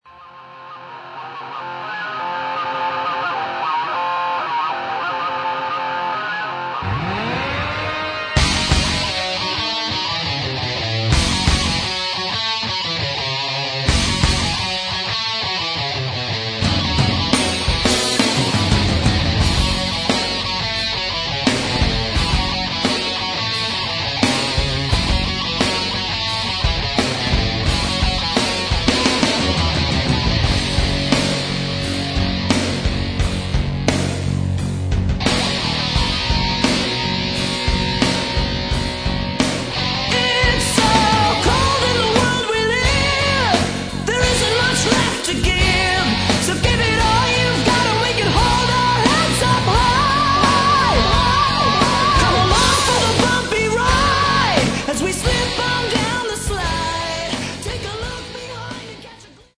Metal
это однозначно направленный мелодичный тяжёлый металл